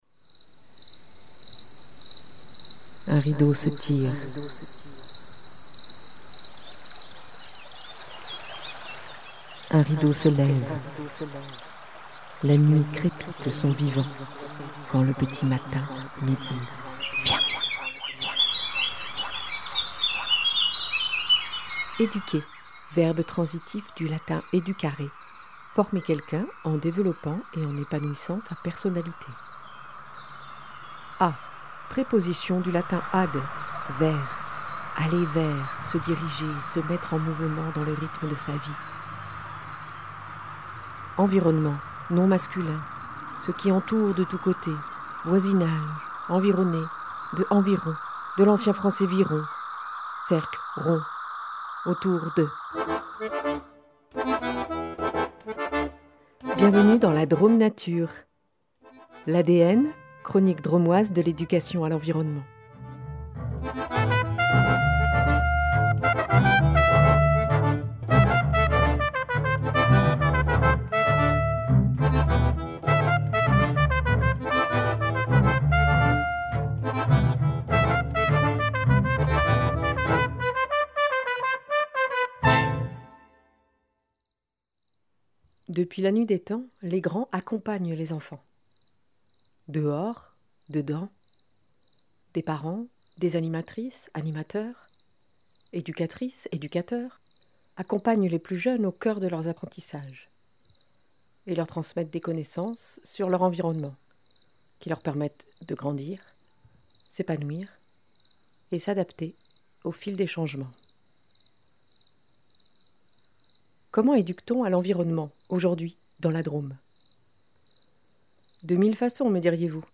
Émission Drômoise de l’Éducation à l’Environnement.